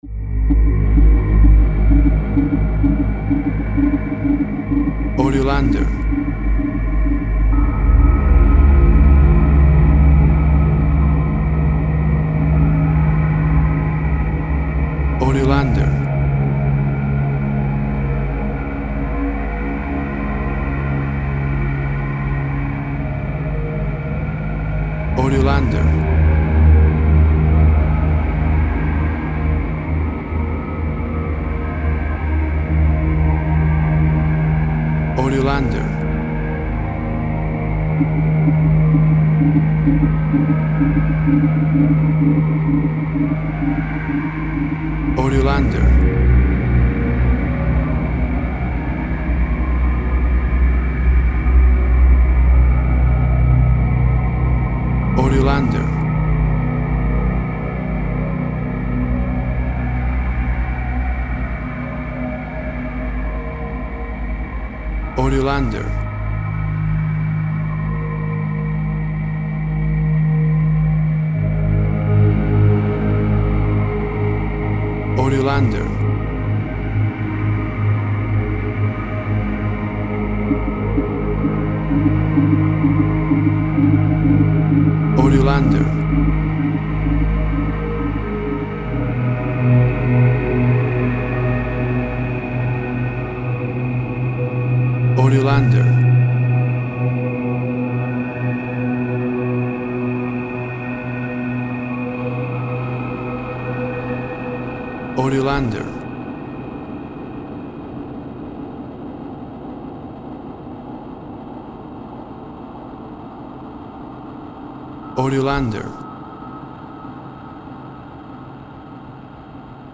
A classic piece of scary horror music with sca.